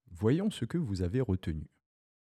KGbaBWyGj2M_Quiz-historique-bip.wav